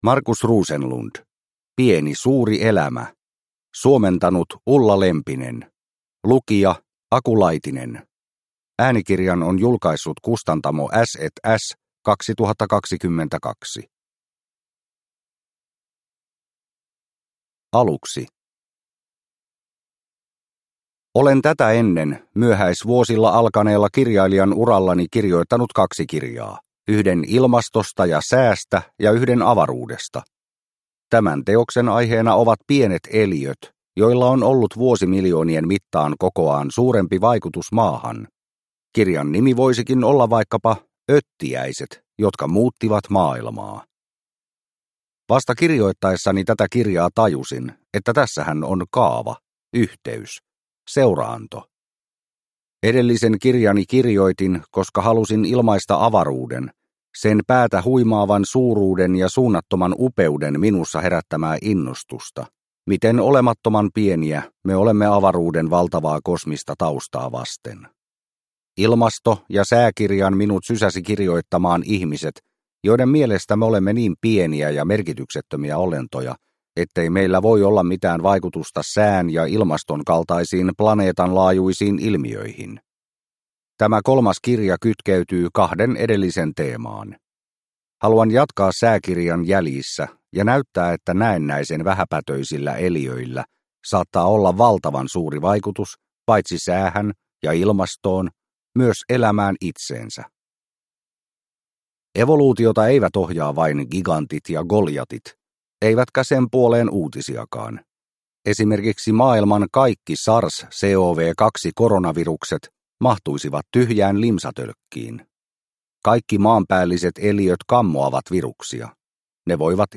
Pieni suuri elämä – Ljudbok – Laddas ner